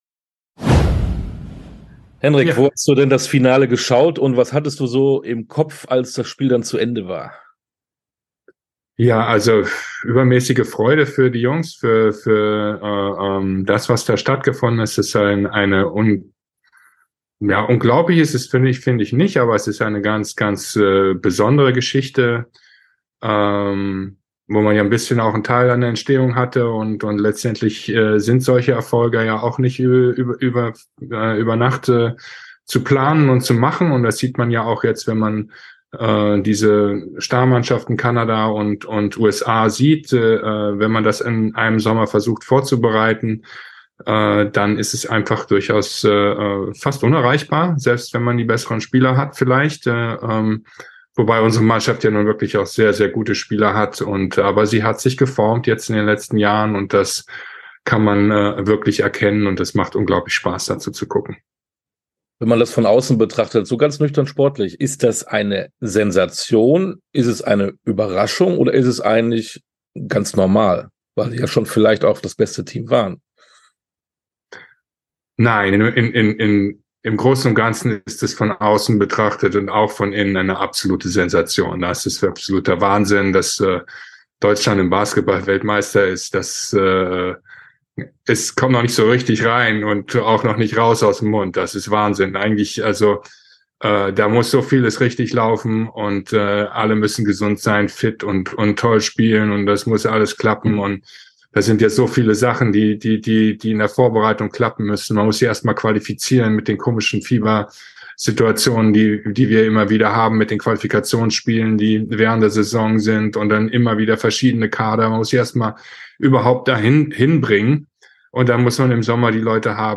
Sportstunde - Interview mit Henrik Roedl ~ Sportstunde - Interviews in voller Länge Podcast